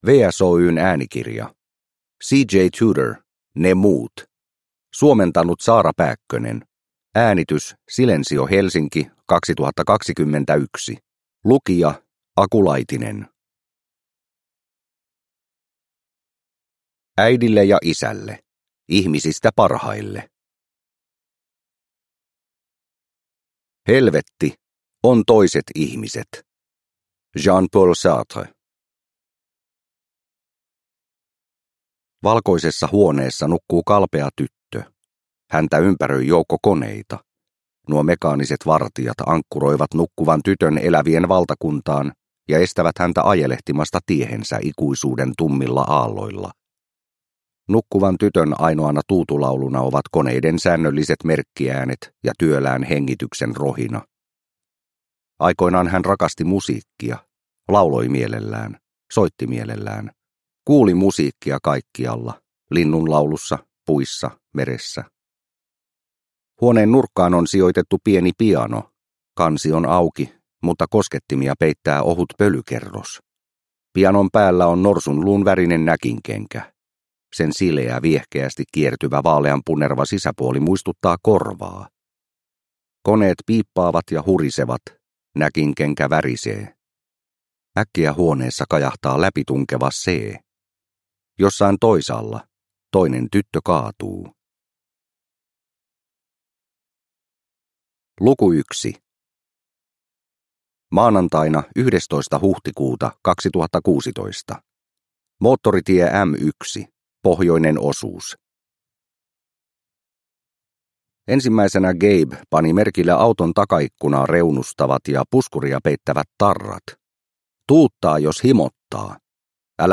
Ne muut – Ljudbok – Laddas ner